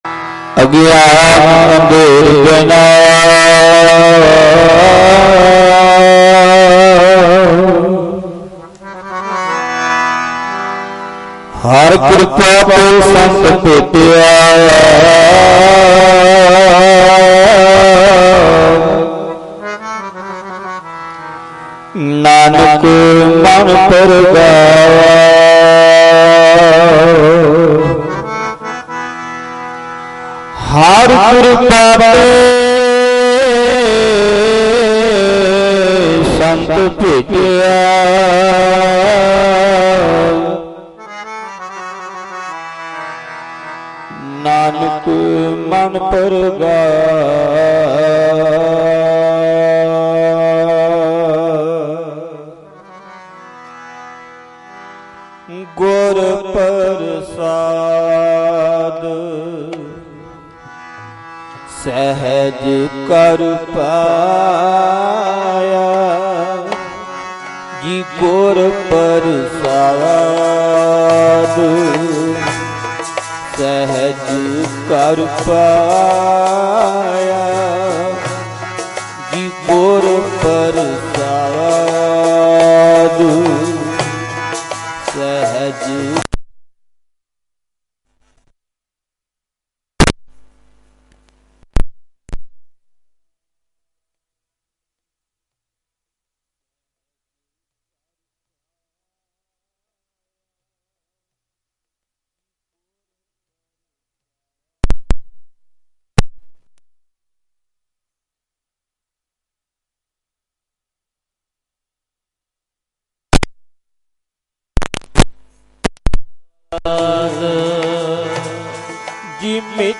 Mp3 Diwan Audio by Bhai Ranjit Singh Ji Khalsa Dhadrianwale
Live_Gurmat_Samagam_Poohli_Bathinda_9_April_2025_Dhadrianwale.mp3